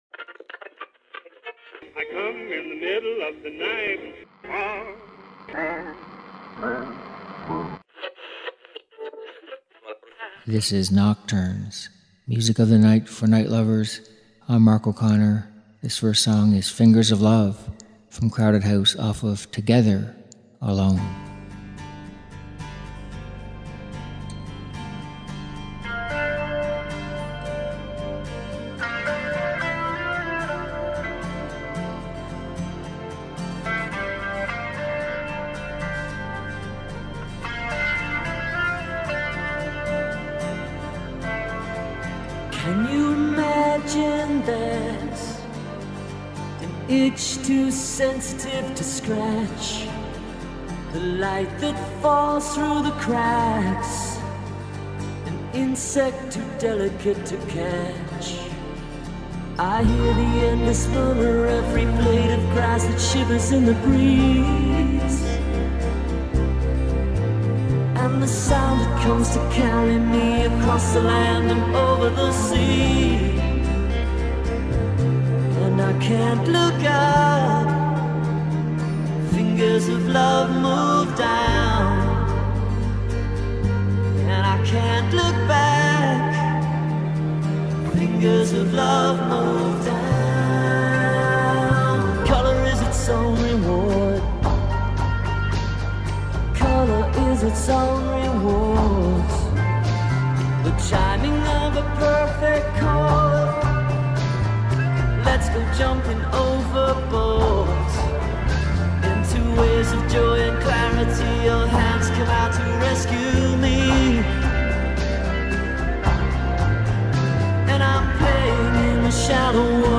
Weekly Program of darker music meant for nighttime listening.